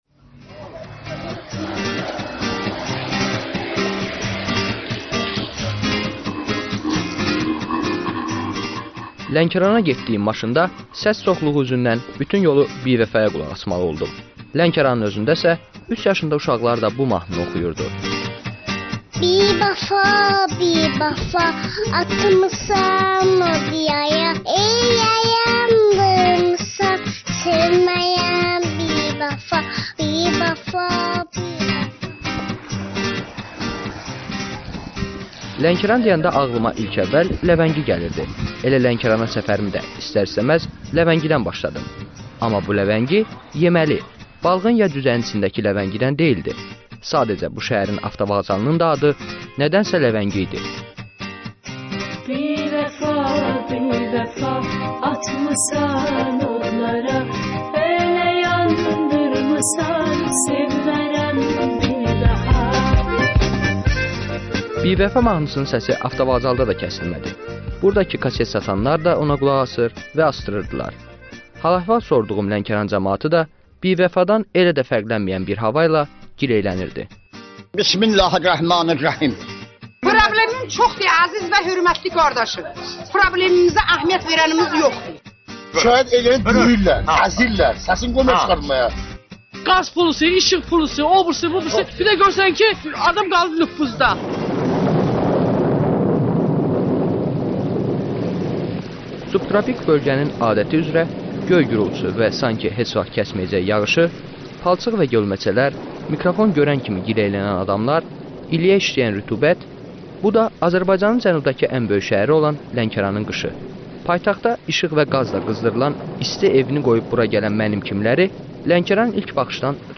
Bölgələrə səfərlər sonunda hazırlanan reportajların səsləndiyi "Azərbaycan şəkilləri" proqramı